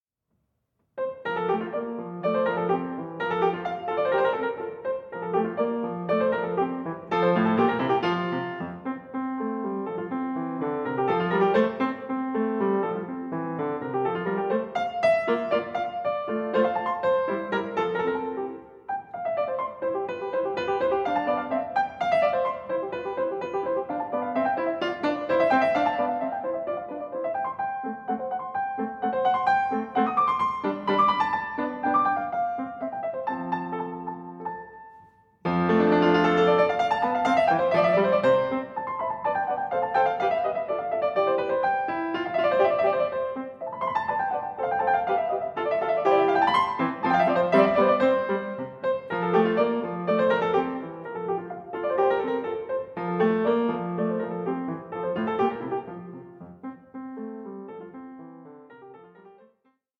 Larghetto 4:20